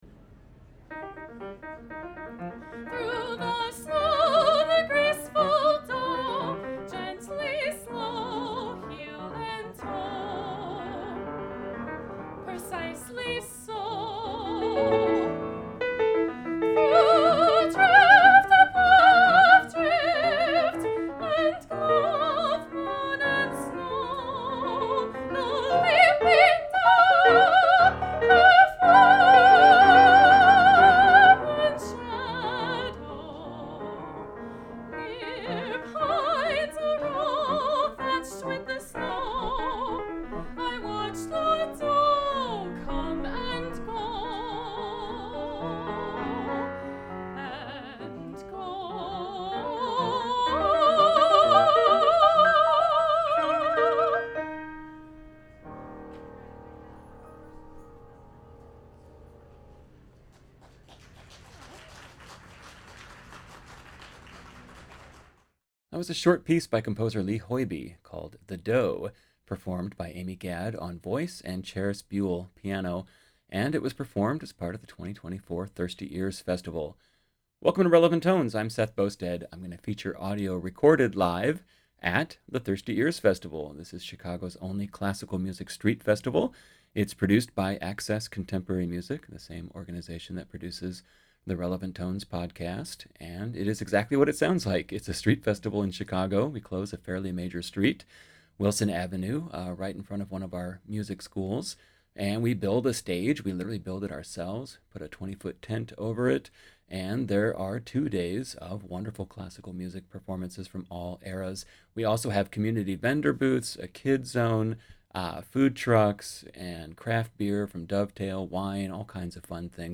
Chicago’s only classical music street festival